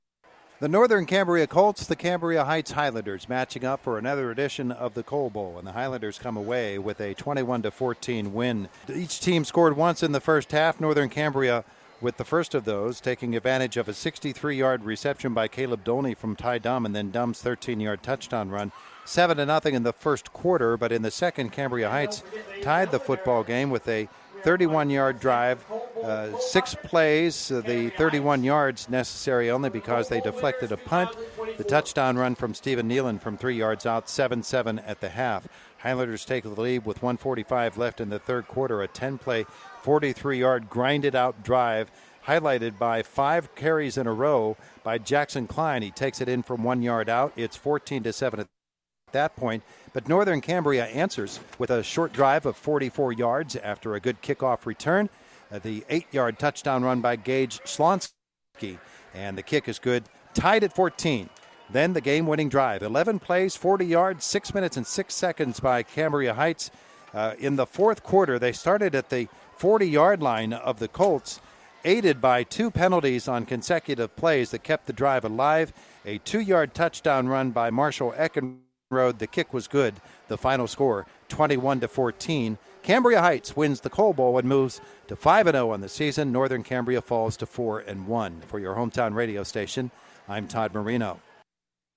recap